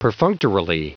Prononciation du mot perfunctorily en anglais (fichier audio)
perfunctorily.wav